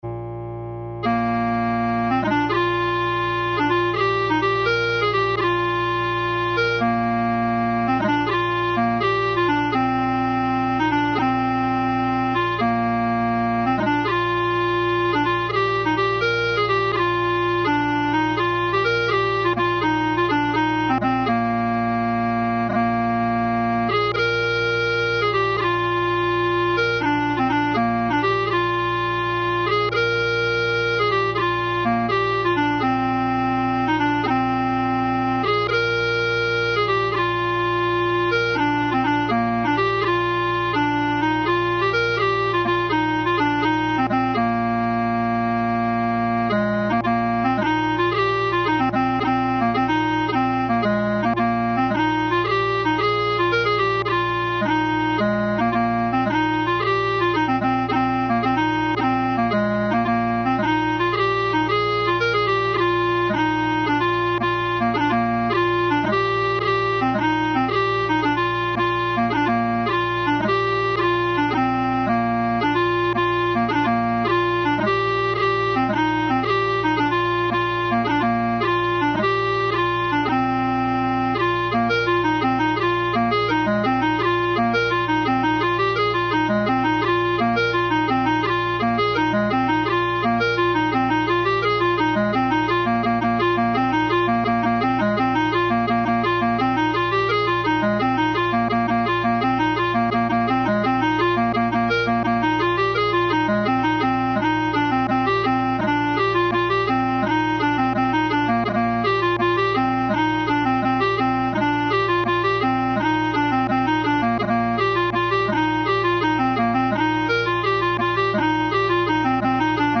March 84 bpm
Strathspey 112 bpm
Reels 87 bpm
Slow Air 80 bpm
Jigs 115 bpm